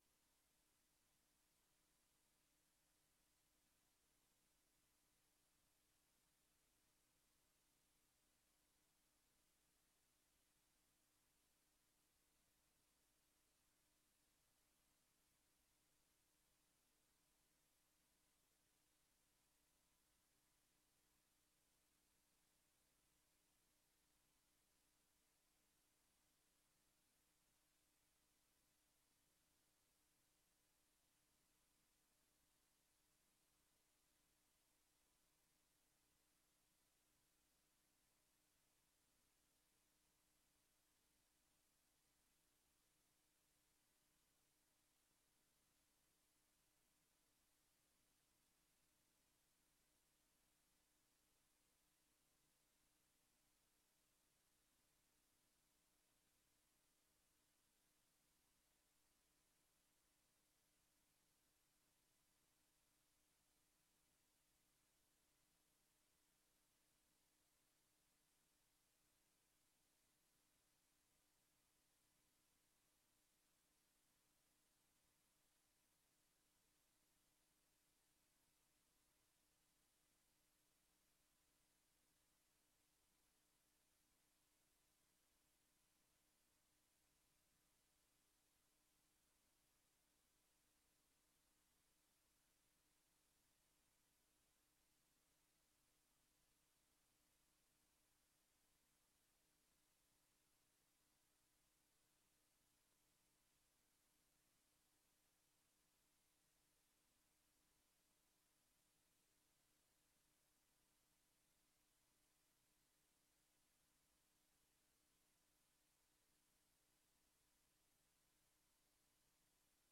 Raadzaal